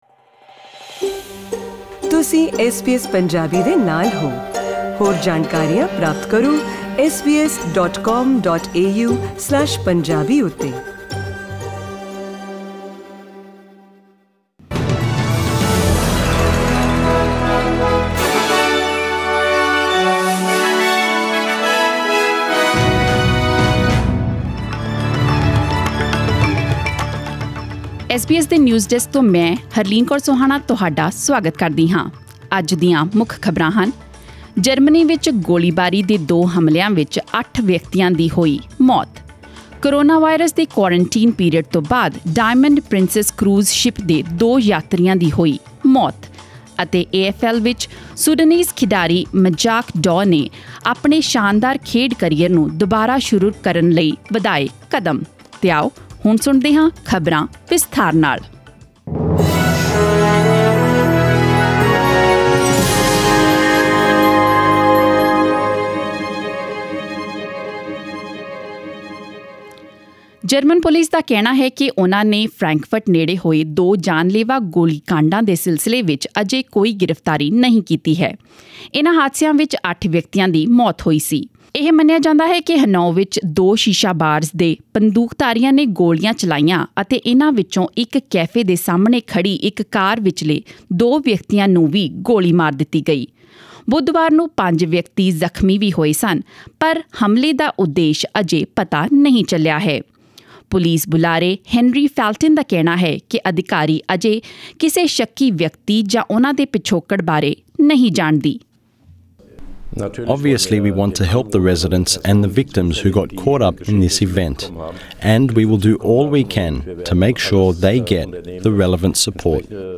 In today’s news bulletin: Two shooting attacks in Germany leave eight people dead; two passenger deaths from the Diamond Princess cruise ship after a quarantine period because of coronavirus and in the A-F-L, Sudanese player Majak Daw makes progress to resume his stellar sporting career.